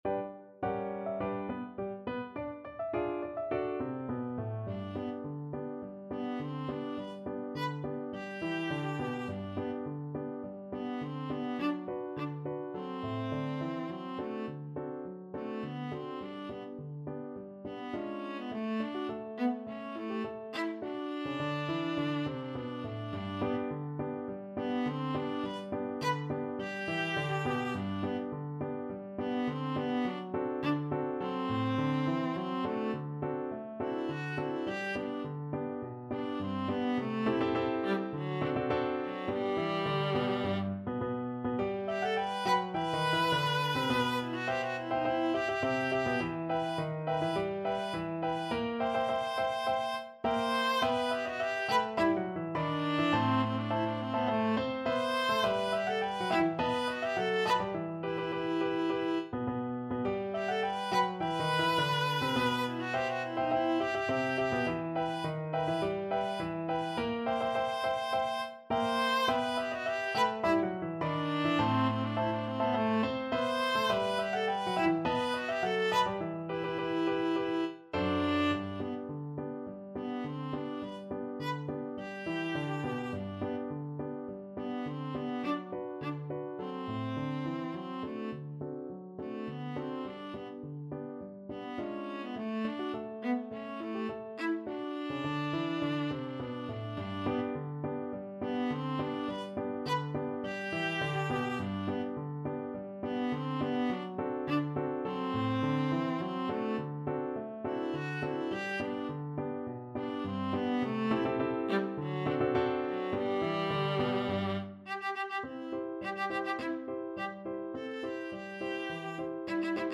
2/2 (View more 2/2 Music)
Quick March = c.104
Classical (View more Classical Viola Music)